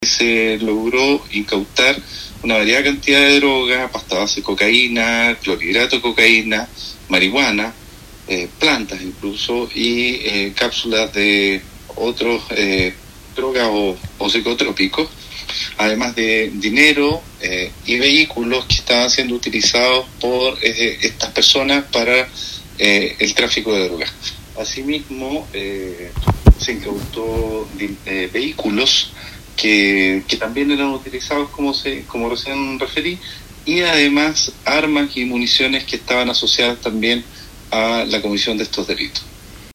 Además, por primera vez en la Región de Los Ríos se concretará una formalización por el delito de lavado de activos. Así lo explicó el Fiscal Soto, quien detalló que en esa línea de investigación se desbarató también un clan familiar.